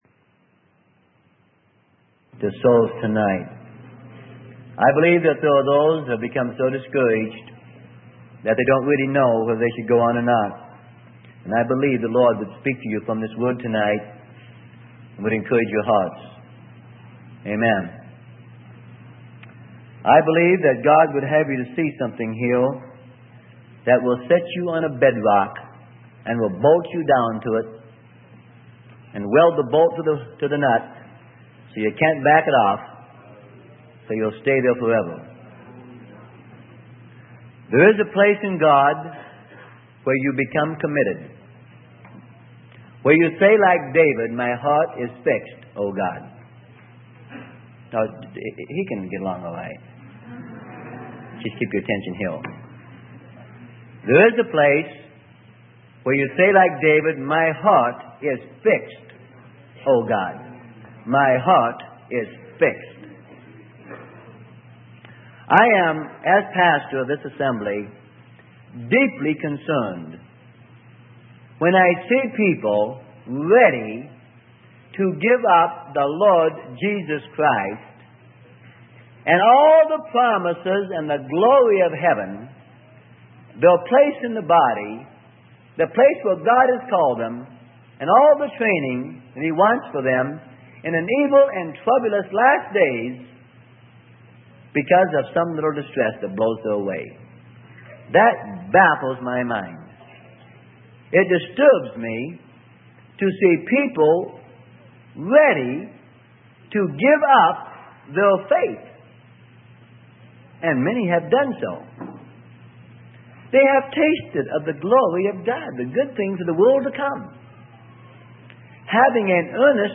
Sermonette: Romans 8:35 - Freely Given MP3 Library